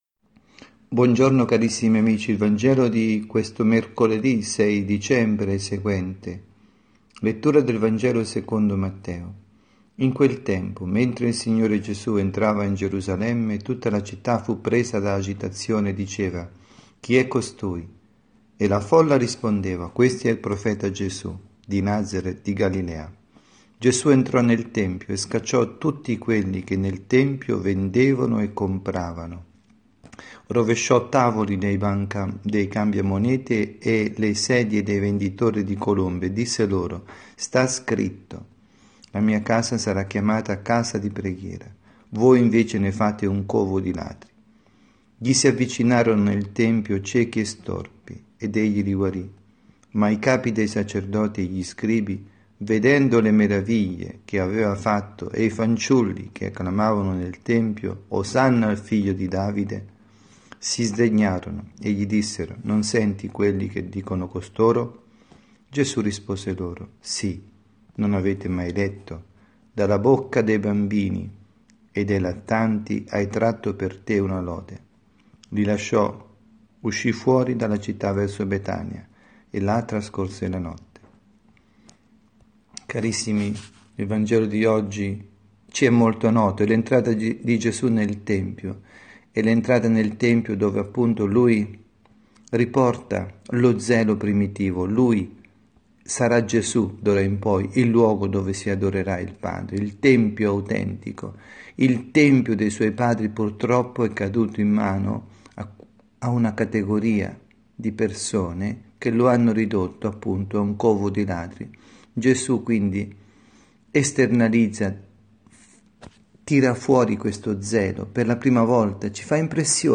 Omelia
dalla Casa di Riposo S. Marta